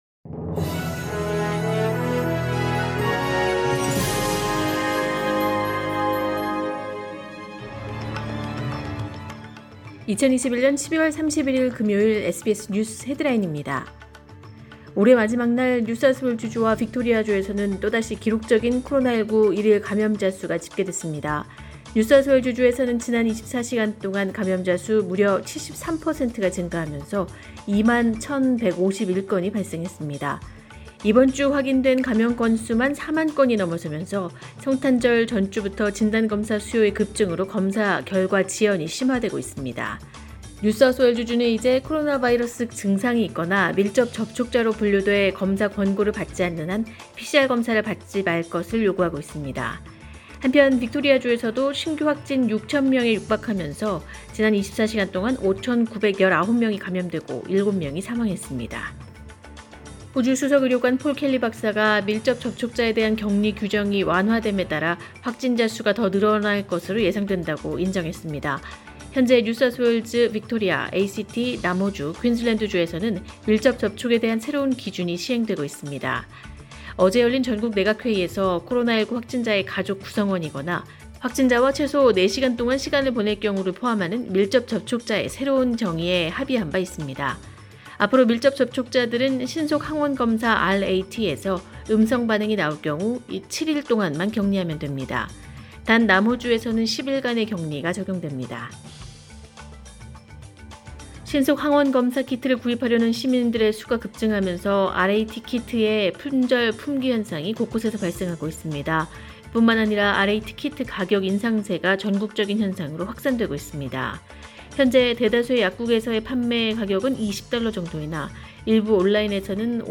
“SBS News Headlines” 2021년 12월 31일 오전 주요 뉴스
2021년 12월 31일 금요일 오전의 SBS 뉴스 헤드라인입니다.